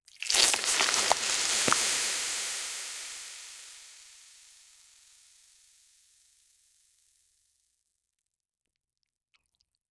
FOAM - Soapy Bubbles & Fizzling
-foam---soapy-bubbles-m7ujivde.wav